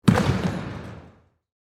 Slam Dumk is a free sfx sound effect available for download in MP3 format.